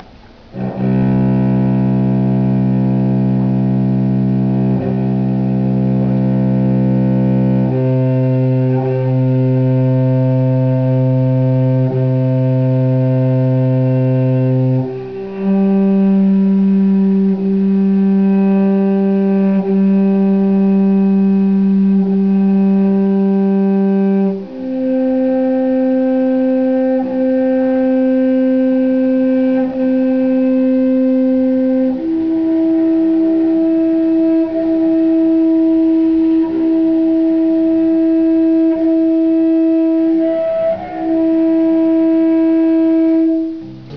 1. Töne auf einer gestrichenen Saite (Cello)
unteres Fenster, 44 Sekunden Cello C-Saite, tiefste Saite.
aufgetragen ist das Mikrofonsignal gegen die Zeit für Grundton und verschiedene Flageolet-Töne auf dieser Saite.
Abb. 01-03: Die Cello-Saite mit etwa 64 Hz Grundfrequenz, mit dem Bogen gestrichen.
Die Harmonischen reichen bis über 3200 Hz hinaus, also bis über die Nummer 3200/64 = 50.
Zeit: 0 -6 Sec. die Grundschwingung 64 Hz und die Reihe der Teiltöne, Vielfache von 64 Hz.